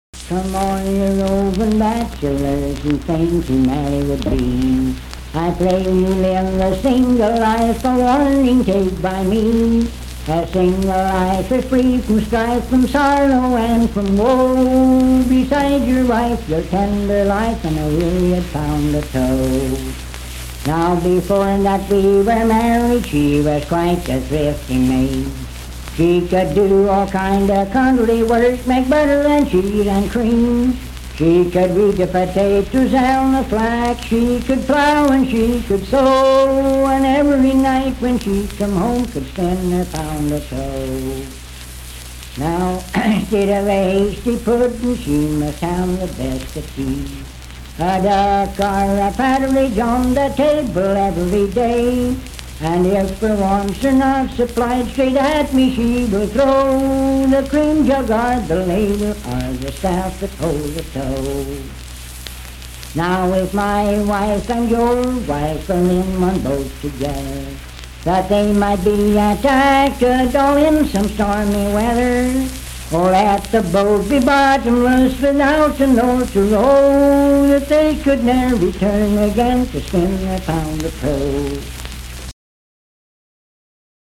Unaccompanied vocal music
Verse-refrain 3(8).
Performed in Sandyville, Jackson County, WV.
Voice (sung)